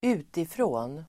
Uttal: [²'u:tifrå:n]